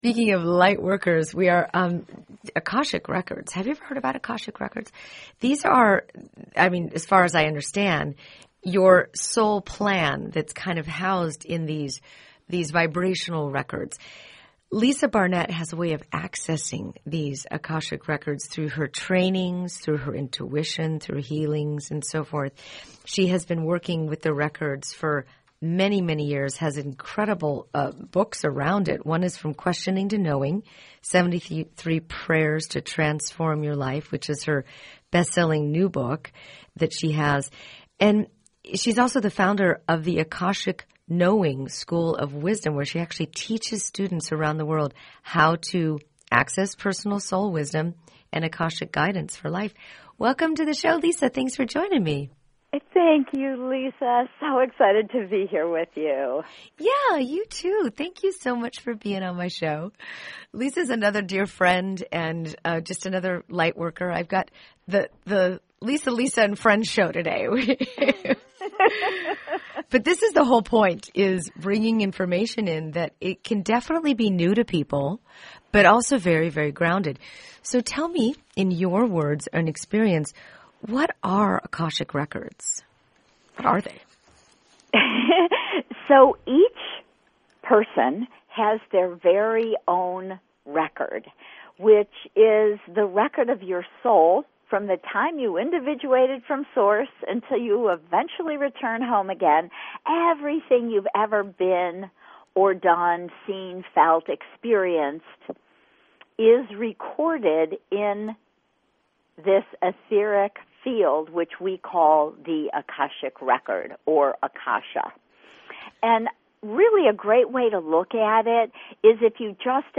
If you’re not familiar with, but have always been curious about, Akashic Records — what they are and how they pertain to you, then listen to today’s interview.